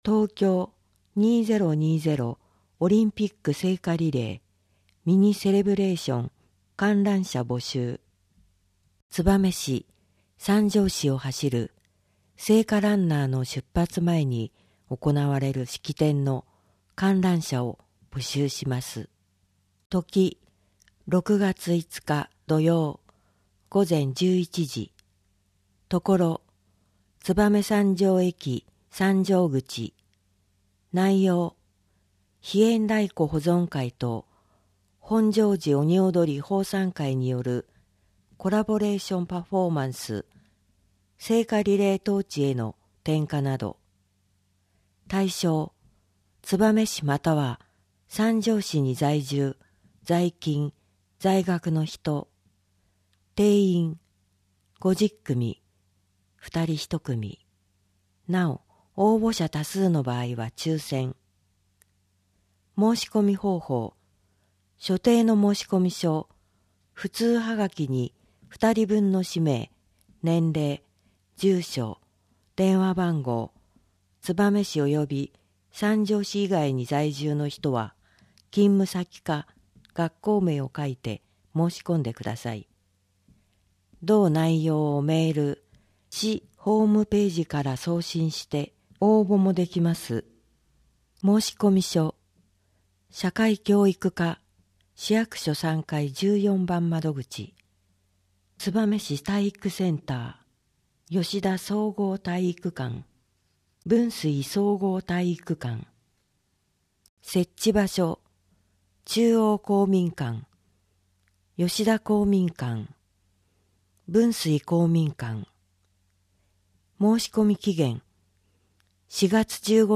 声の広報は、広報つばめを音読・録音したもので、デイジー版とMP3版があります。